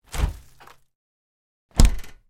На этой странице собраны разнообразные звуки сундуков: скрип дерева, стук металлических застежек, глухой гул пустого пространства внутри.
Звук открытия и закрытия сундука